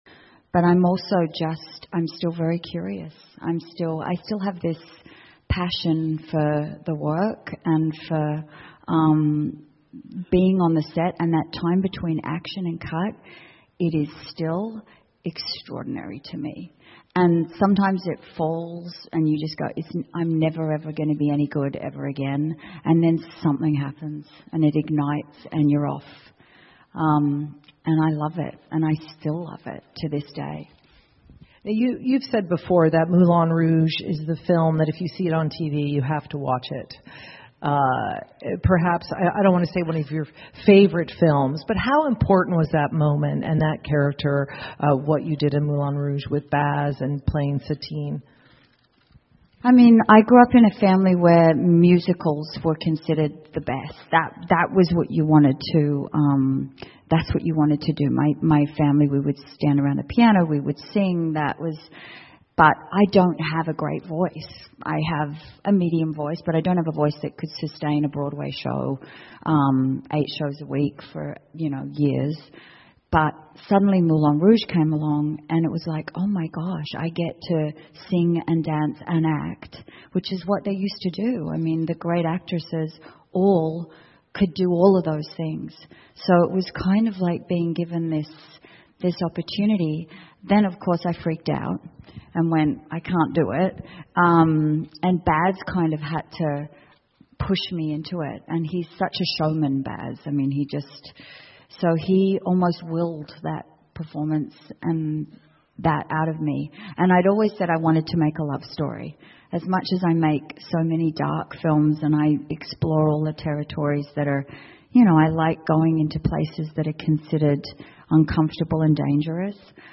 英文演讲录 妮可·基德曼：我的演艺生涯(2) 听力文件下载—在线英语听力室